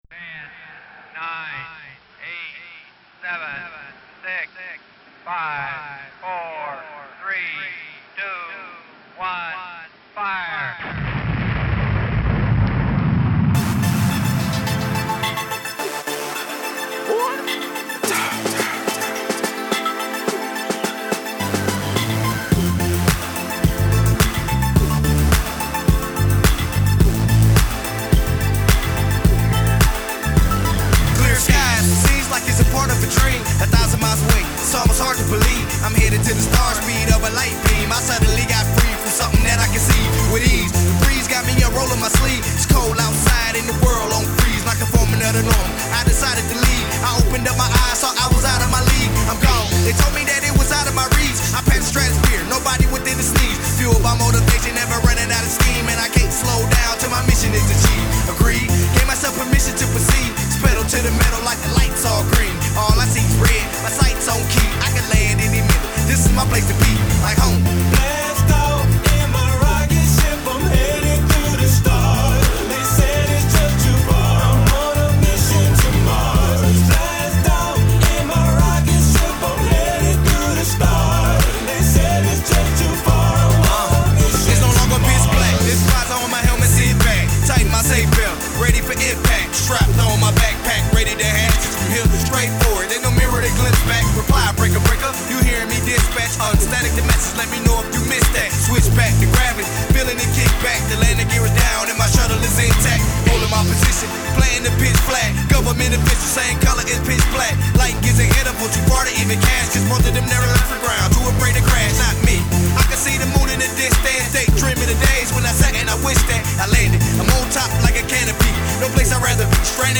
futuristic party track